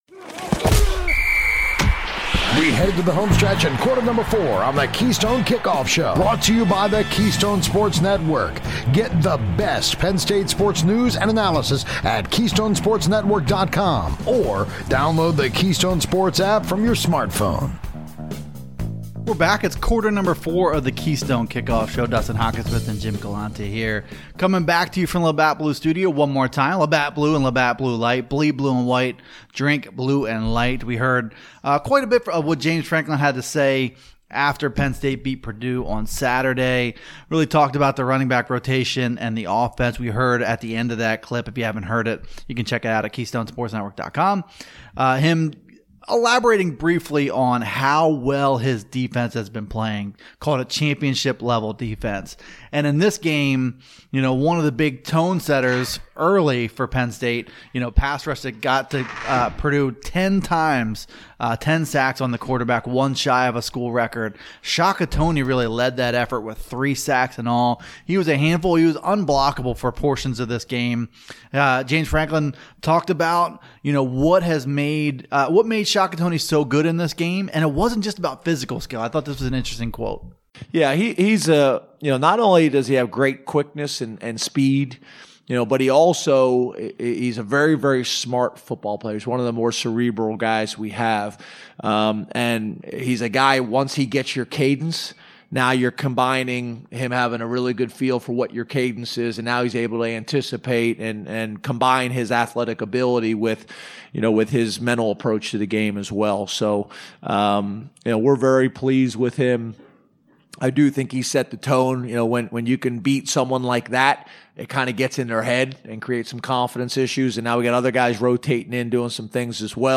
We hear some more soundbites from coach Franklin on Shaka Toney, first half success, KJ Hamler and more!